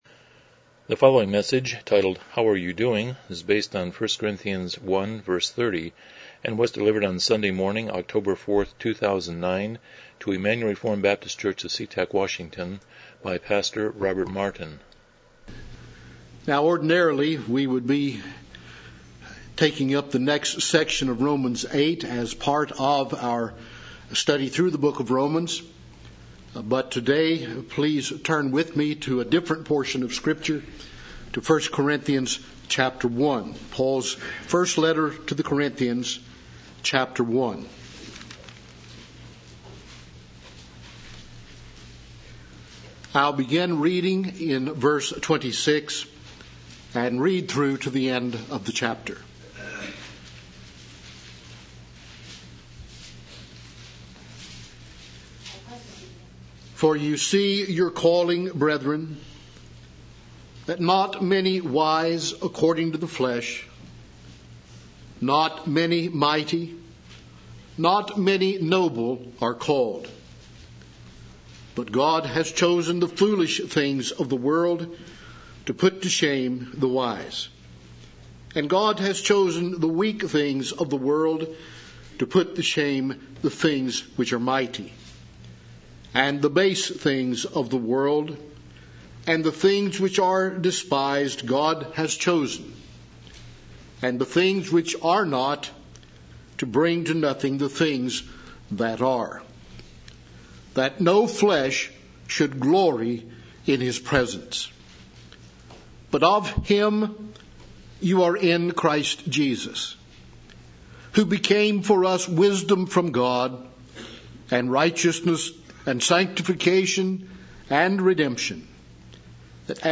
1 Corinthians 1:30 Service Type: Morning Worship « 50 The God of the Theocracy